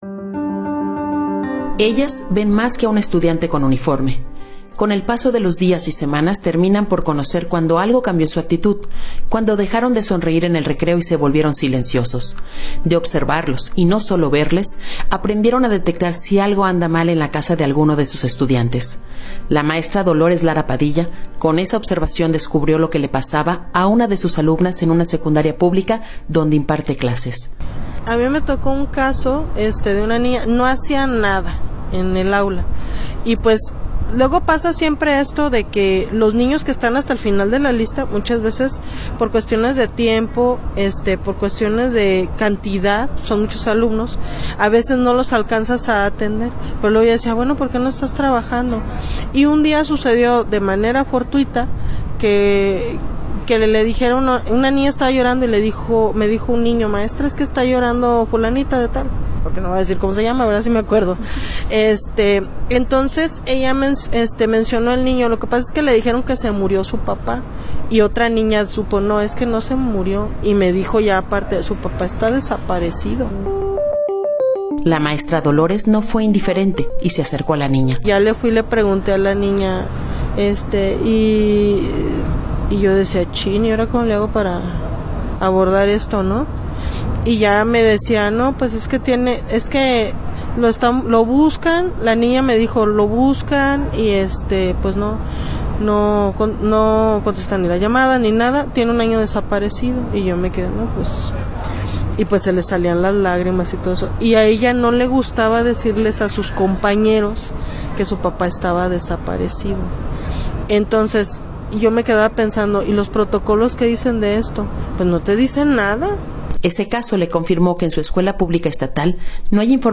Ante la ausencia de protocolos claros y capacitación oficial, maestras de escuelas públicas en Jalisco relatan cómo enfrentan con empatía y sensibilidad los casos de alumnos afectados emocionalmente por la desaparición de familiares.